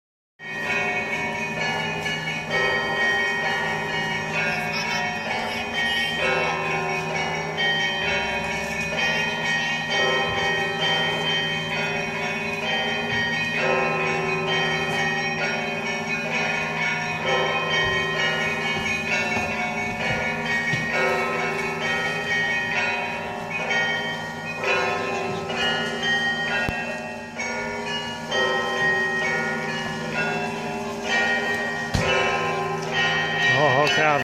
A nebeský areál? To je nejvyšší zvonice, která skýtá výhled do kraje a na zlaté střechy chrámů a která zní hudbou zvonkohry.
Zvonky i velké zvony jsou propojeny důmyslným systémem kladek a lan a hraje se ne ně jako na varhany.
Ruce rozeznívají menší zvonky, šlapáním na dřevěné pedály se pak rozhoupou srdce velkých zvonů.